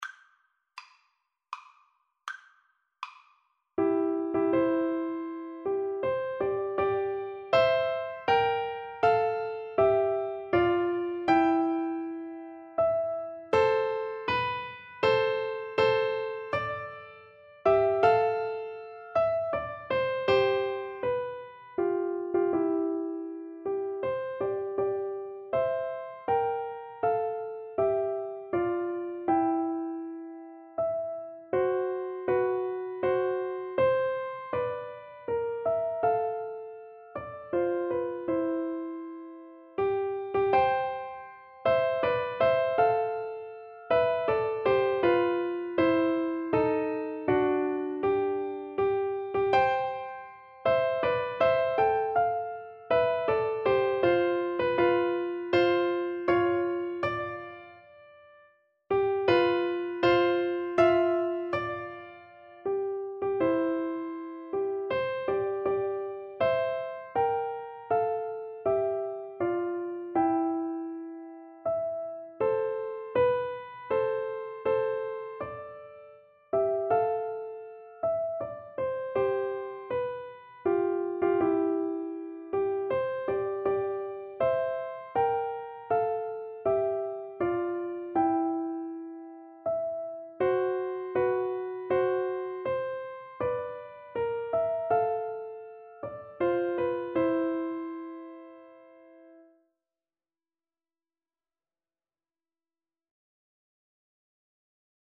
Andante
3/4 (View more 3/4 Music)
Classical (View more Classical Piano Duet Music)